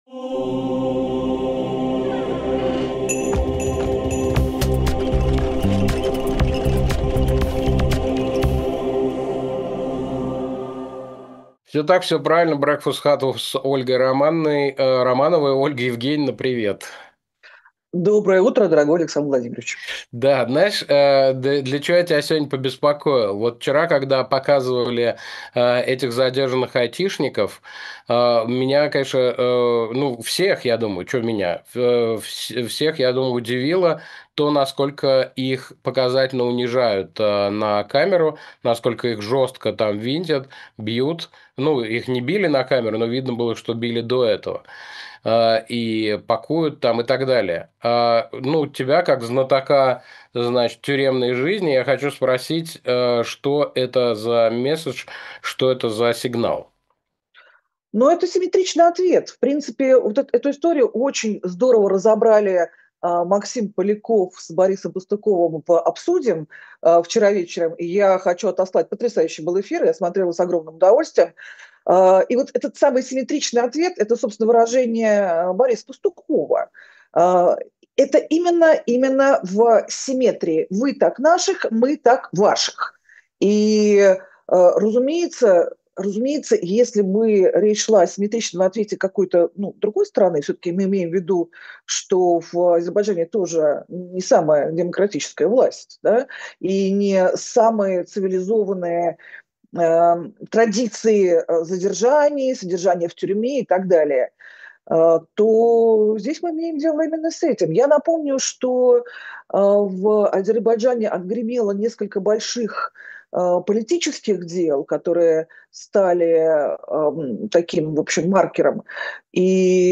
Фрагмент эфира от 2 июля.